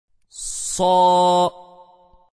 1. Tabii Med (Medd-i Tabii):
Tabii med hareke uzunluğunun iki katı uzatılır.